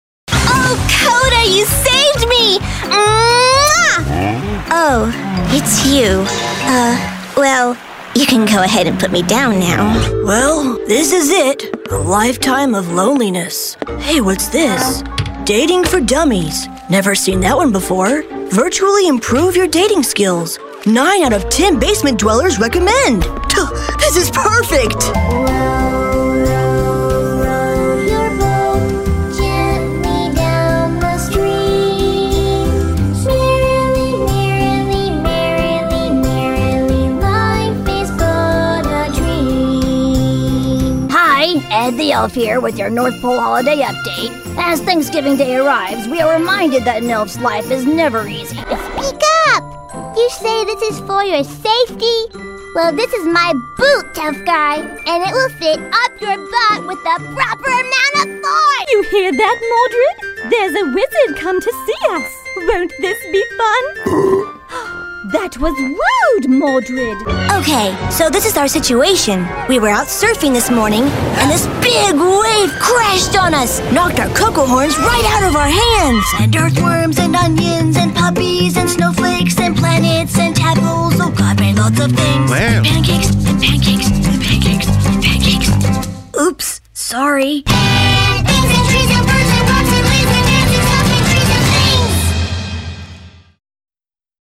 Young Adult, Adult
Has Own Studio
standard us | natural
ANIMATION 🎬